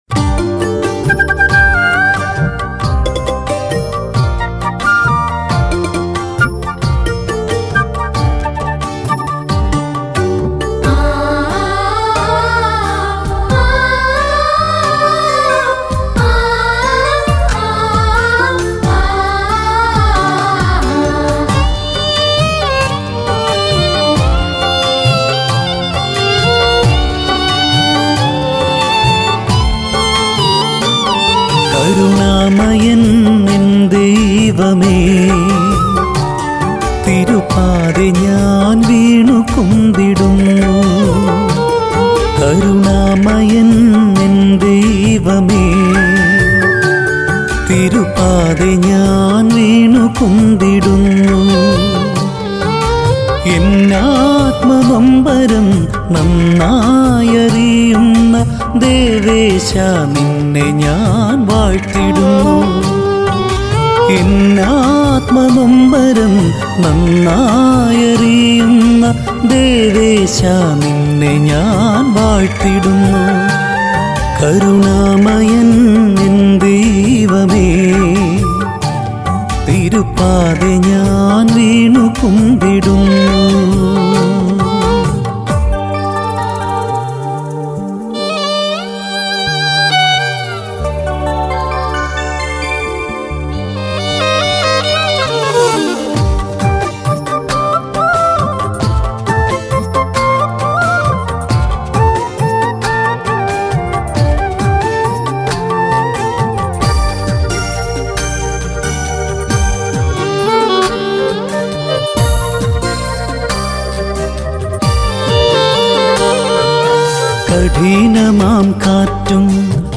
Christian Devotional Songs & Video Albums